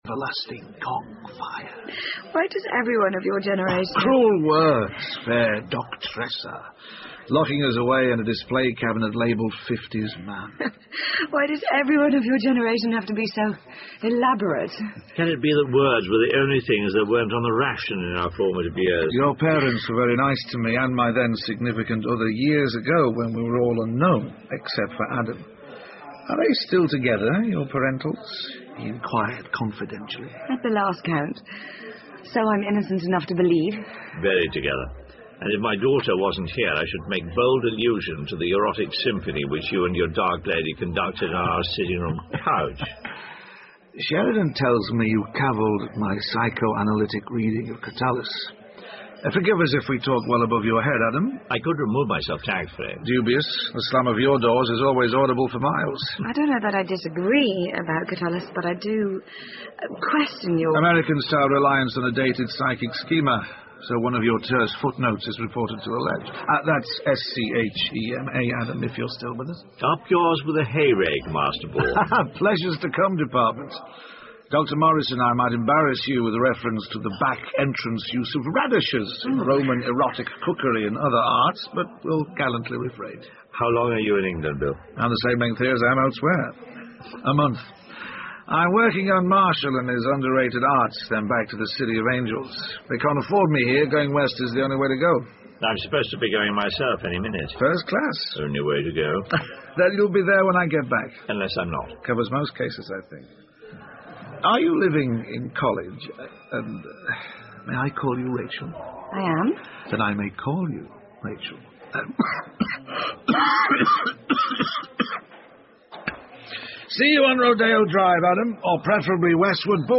英文广播剧在线听 Fame and Fortune - 47 听力文件下载—在线英语听力室